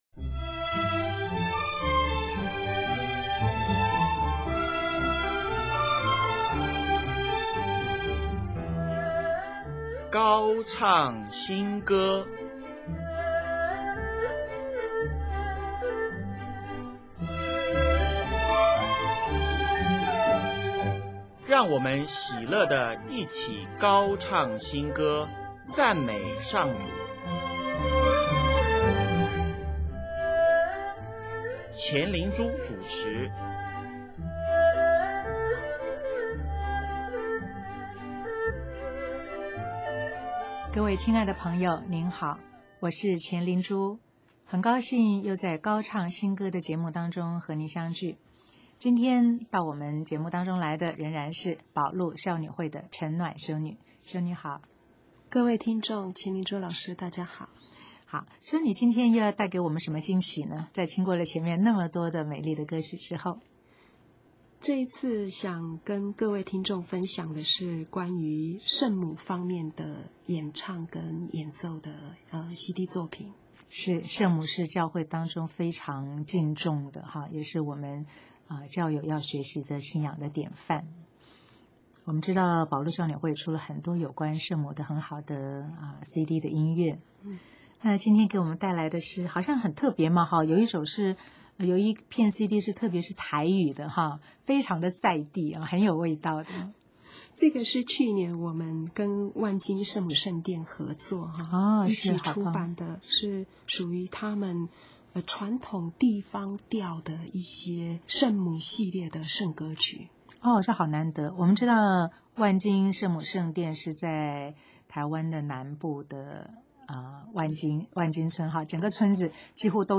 所谓传统调是指西班牙道明会神父当年到台湾开教时，所引进的南管乐风，神父请当地教友为些曲子填上信仰词句。本集播放“至圣至尊圣童贞”、“圣家歌”。